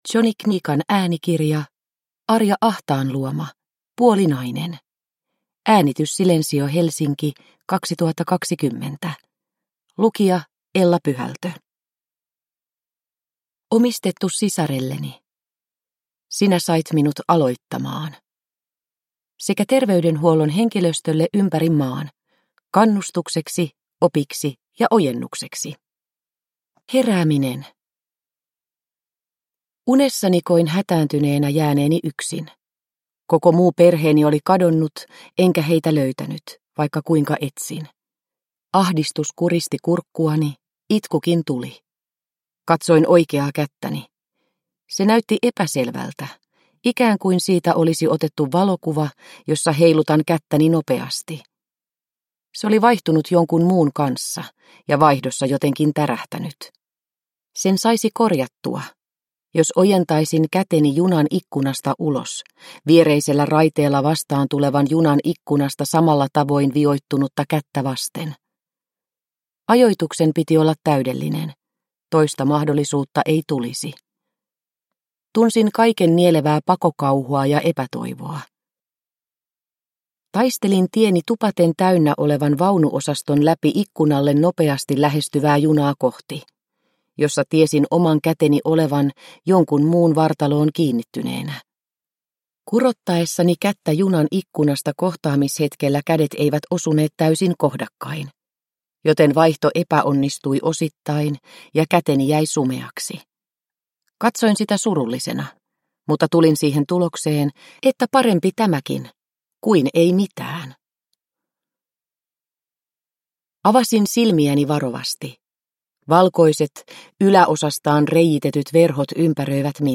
Puolinainen – Ljudbok – Laddas ner